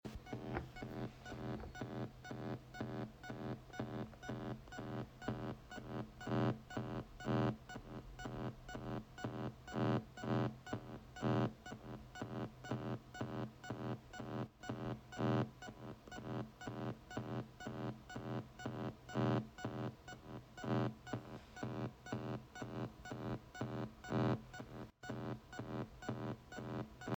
ue boom linux compatibility ambiance texture.ogg